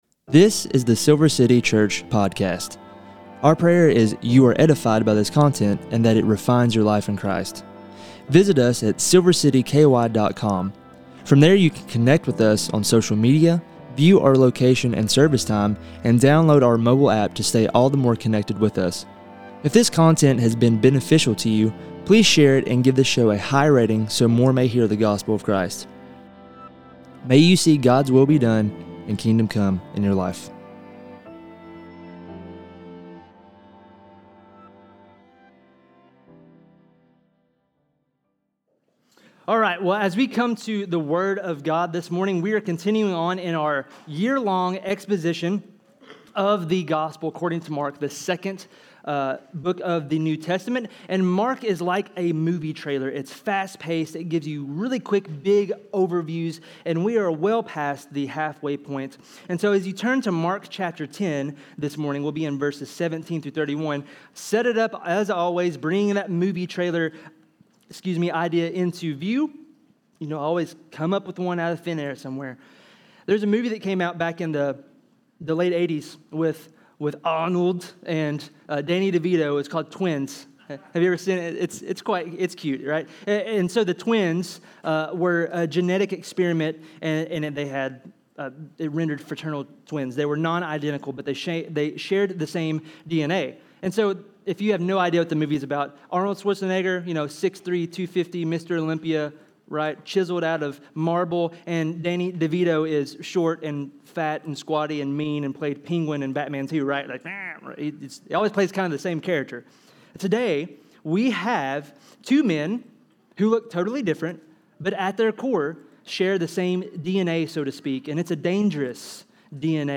Sermons | Silver City Church